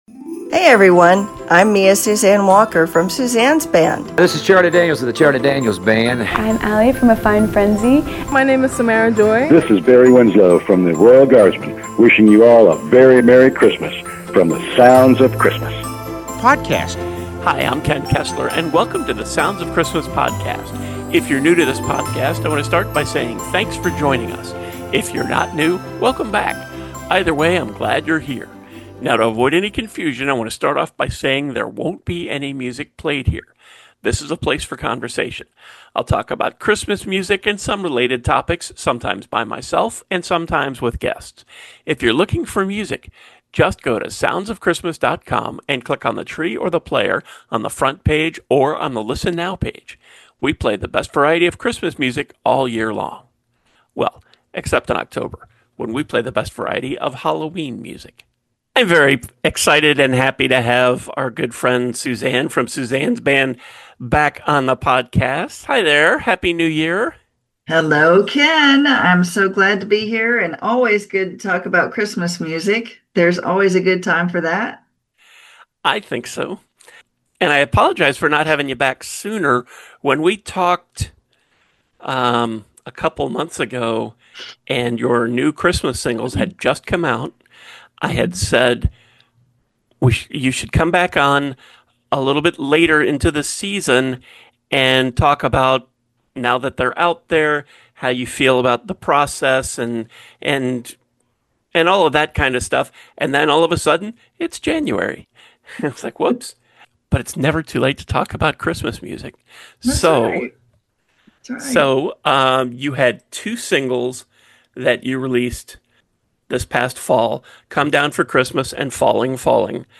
talks to singer/songwriter